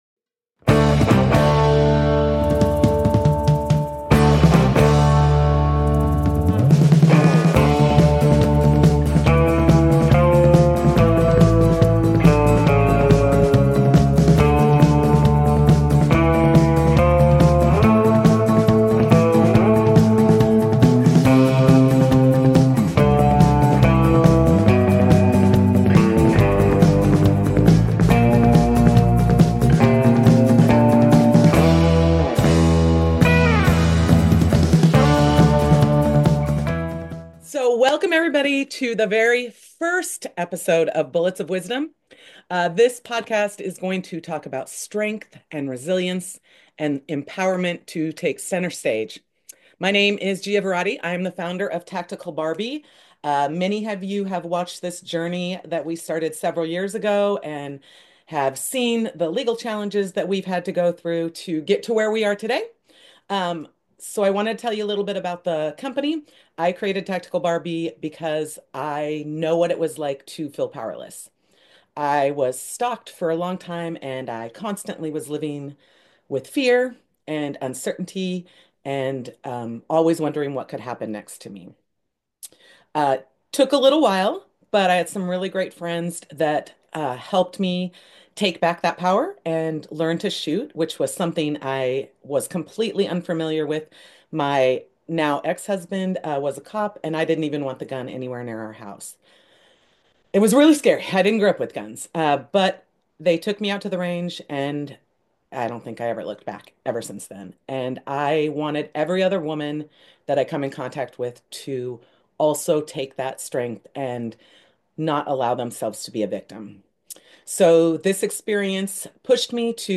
Get ready for a raw, real, and empowering conversation with a true warrior.